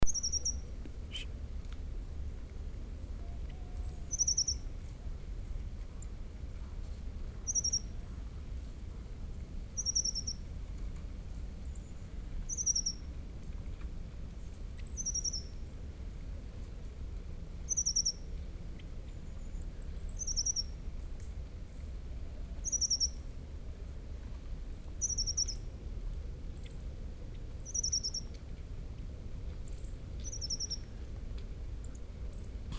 Siberian Accentor and Long-tailed Tit were highly responsive to playback. I recorded both species.
Siberian Accentor (00:33; 2.1 MB)
accentor-siberian001-Prunella-montanella.mp3